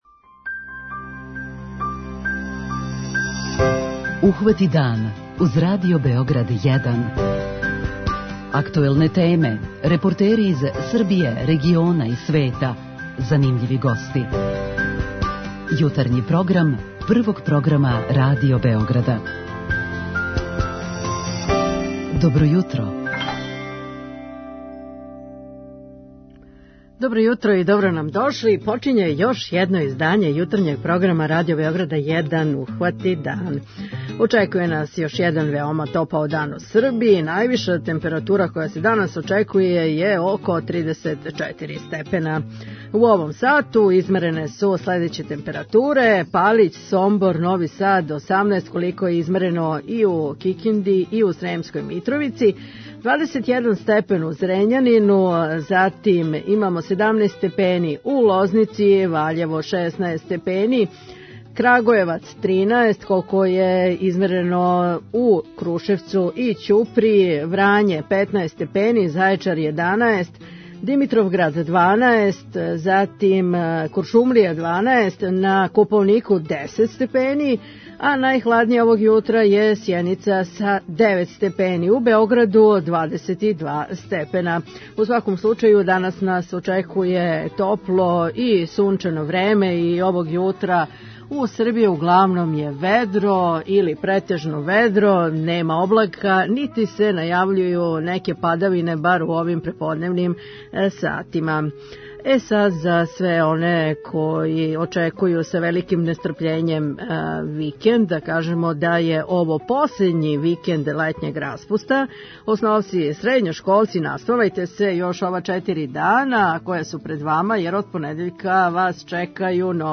преузми : 37.82 MB Ухвати дан Autor: Група аутора Јутарњи програм Радио Београда 1!